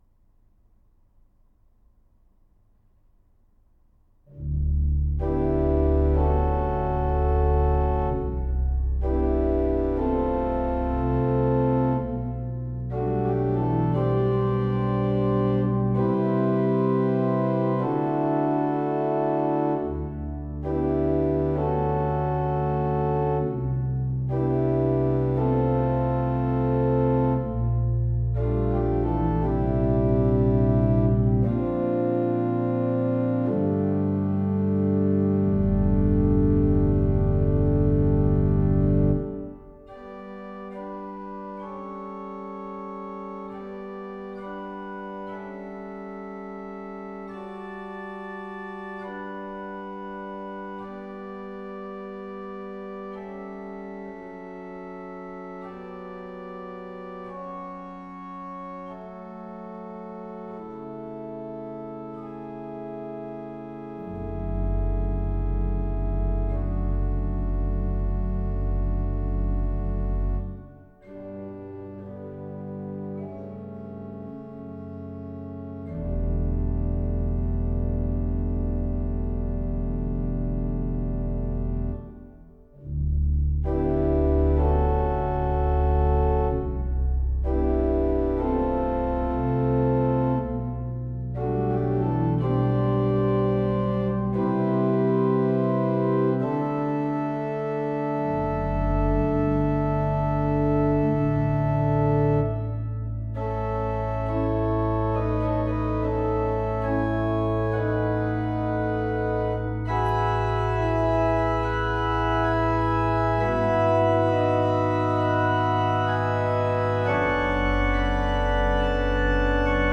organ Duration